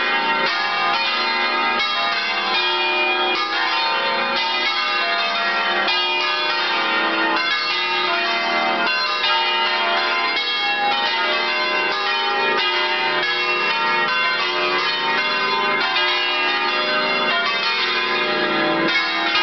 250-campane.mp3